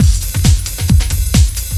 TECHNO125BPM 12.wav